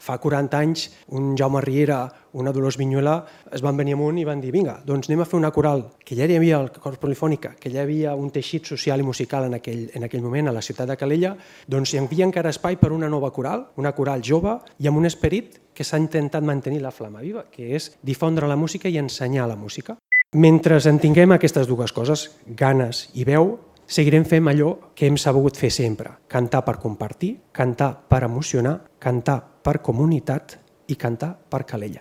En el discurs d’agraïment